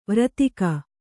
♪ vratika